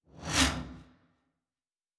pgs/Assets/Audio/Sci-Fi Sounds/Movement/Fly By 07_1.wav at master
Fly By 07_1.wav